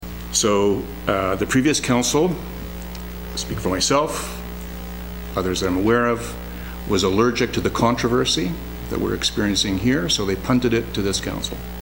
The issue was dealt with at Shire Hall on Thursday during their Committee of the Whole meeting in a packed council chambers and council voted to defer the matter another eight months.
Councillor Bill Roberts apologized to council saying previous councils left this current council to do all the heavy lifting on this decision.